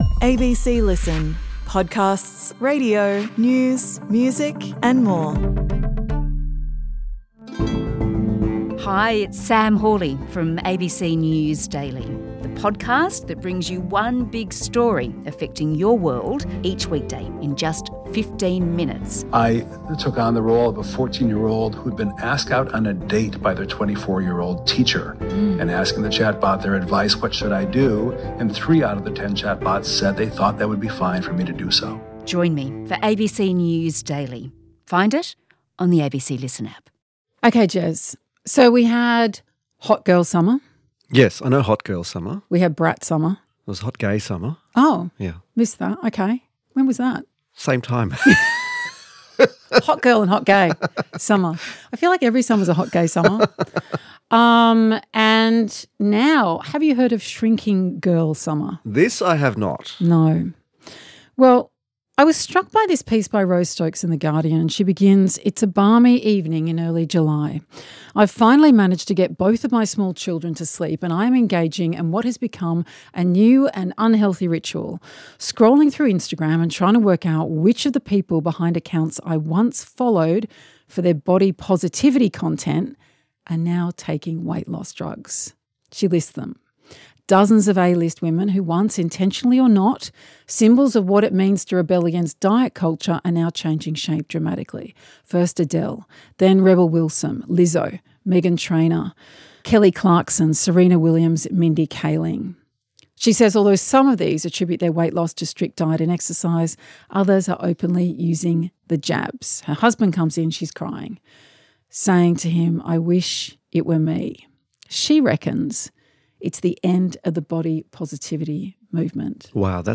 Julia Baird and Jeremy Fernandez chat about the stories you're obsessed with, the stuff you've missed and the things that matter.